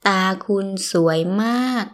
– dtaa – kunn ∨ suay ∧ maag